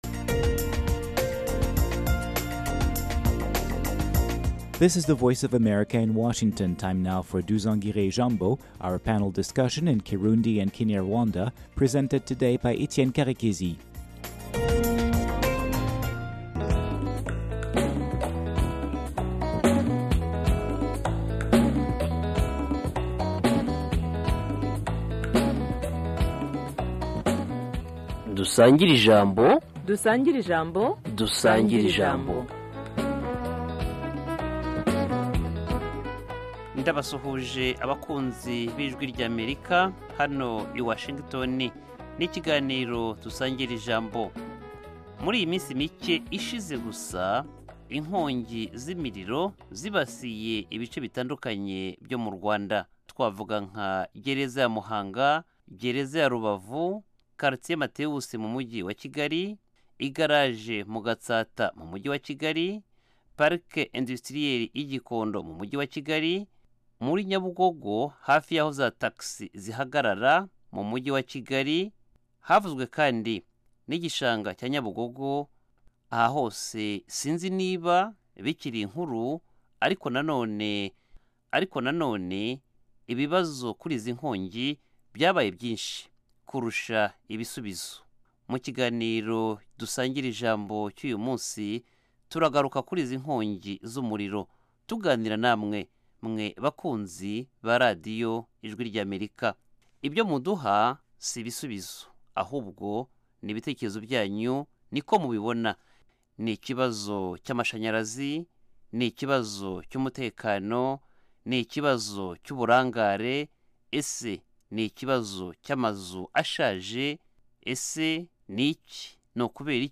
Dusangire-ijambo - Panel discussion and debate on African Great Lakes Region, African, and world issues